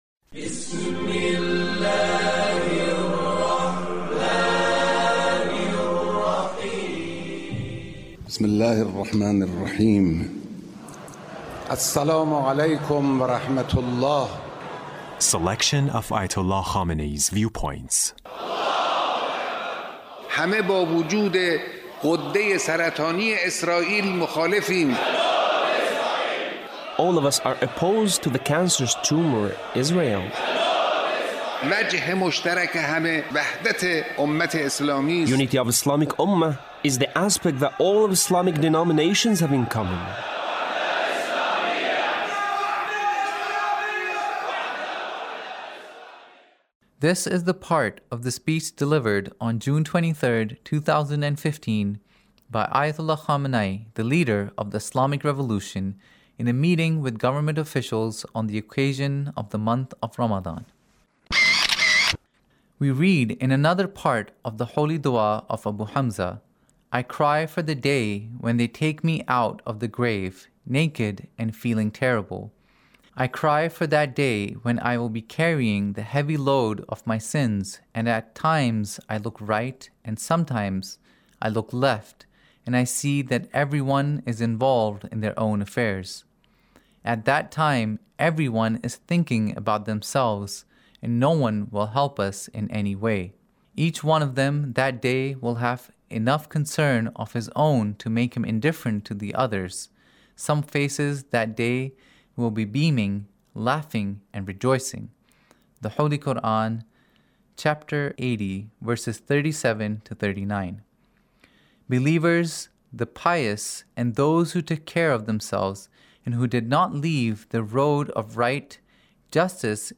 Leader's Speech (1921)
Leader's Speech On The Month of Ramadhan in a Meeting with the Government Officials